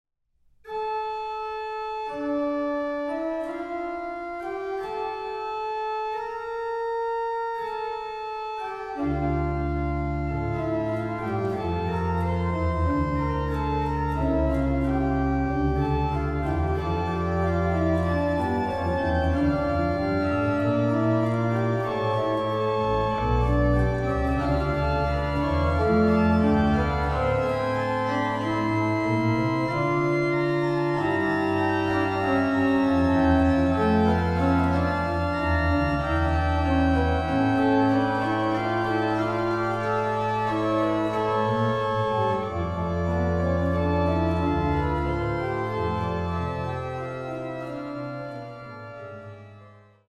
1755 erbaut für Prinzessin Anna Amalia von Preußen